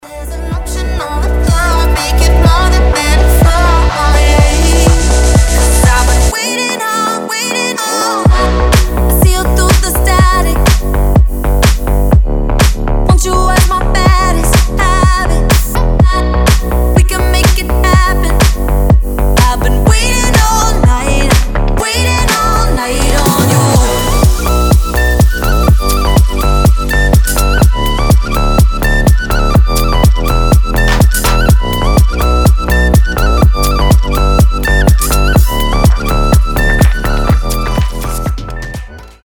женский голос
EDM
Midtempo
Флейта
house
дудка